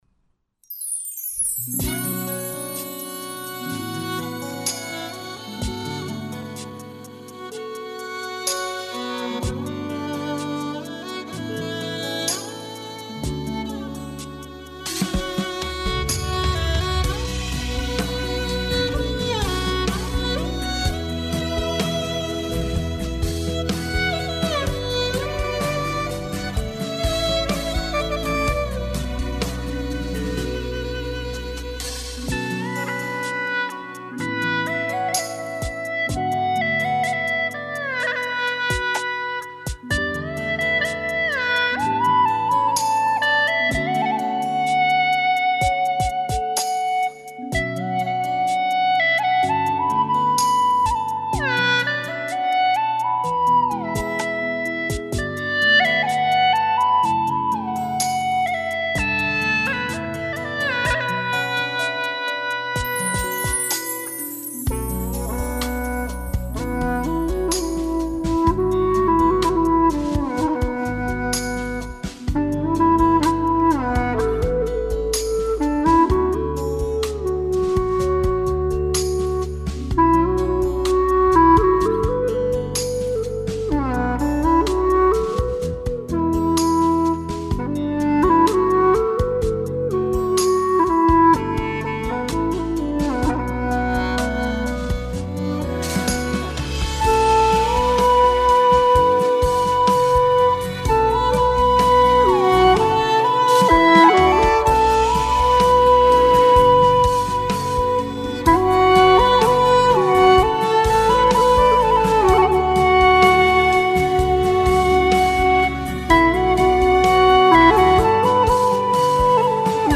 调式 : D 曲类 : 流行
又一首草原风格作品
用大小D调两支葫芦丝完成